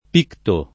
picto [ p & kto ] → pitto